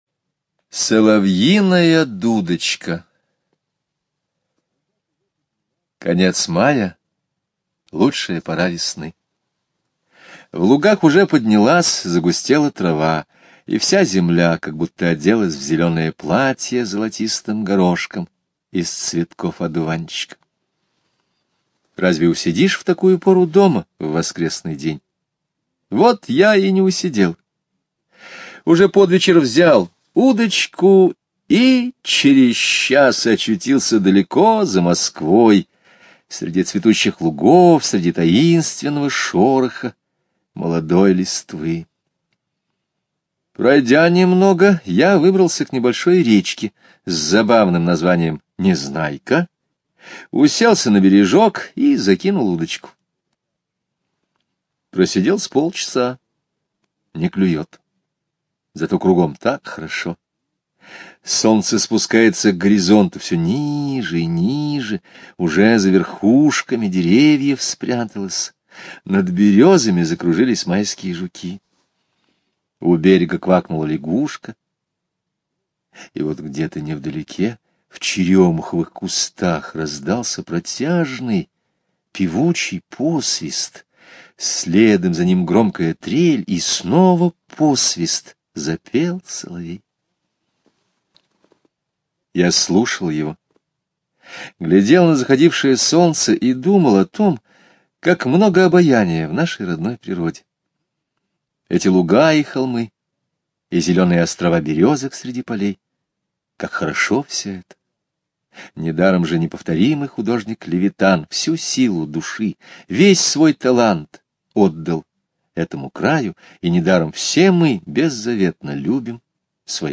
Слушайте Соловьиная дудочка - аудио рассказ Скребицкого Г. Однажды майским вечером автор пошел на рыбалку.